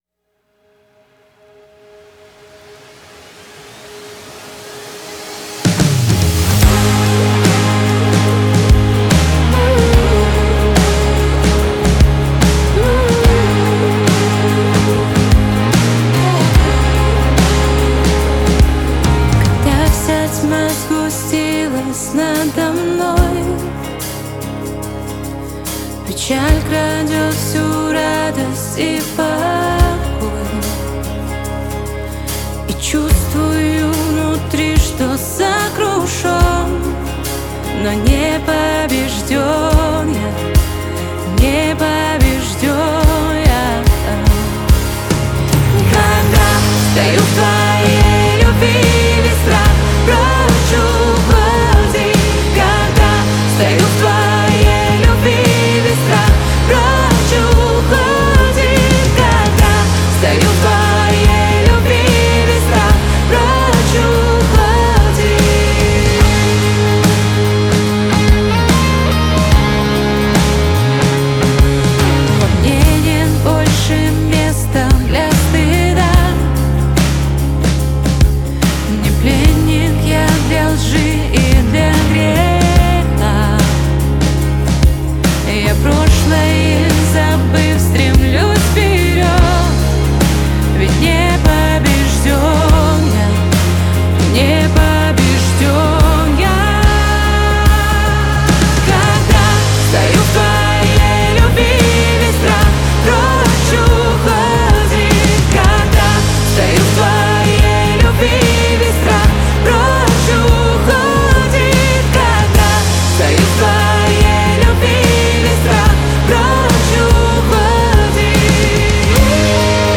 2349 просмотров 2103 прослушивания 244 скачивания BPM: 72